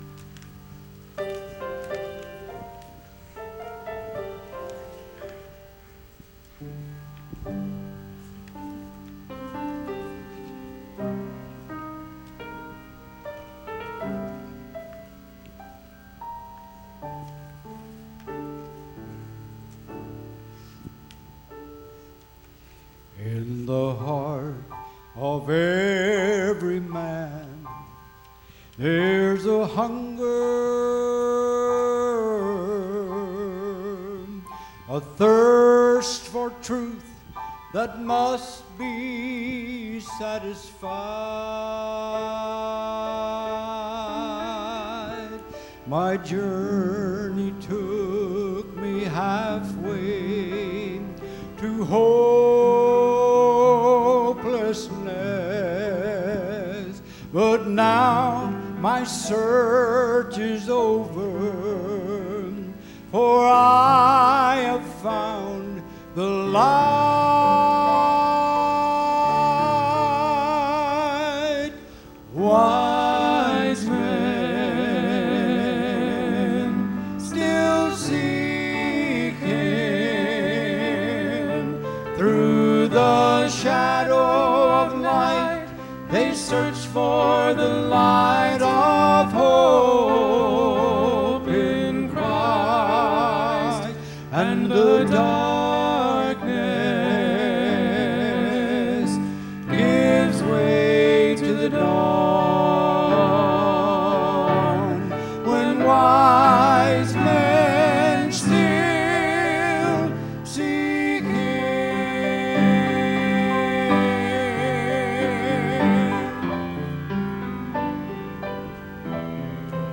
“Wise Men Still Seek Him” – Trio and Choir
wise-men-still-seek-him-trio-and-choir.mp3